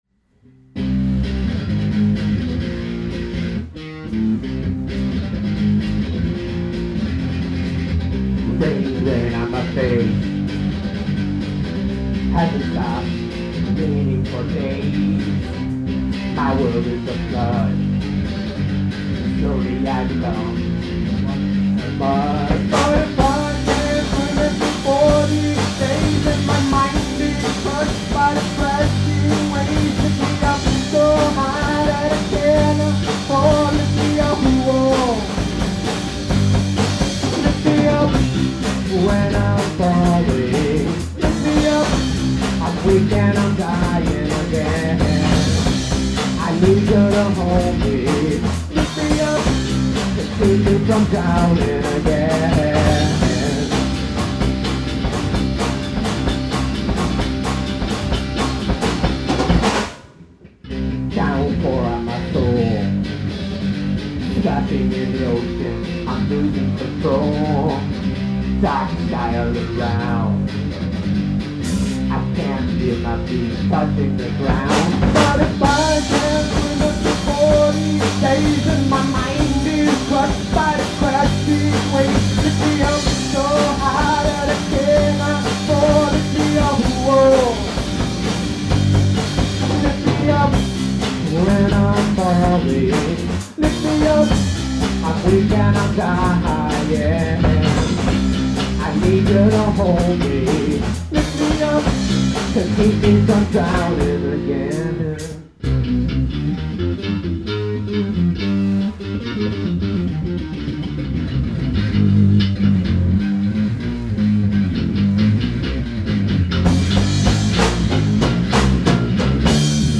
new songs © & live raw rare recordings...
Bass & Vocals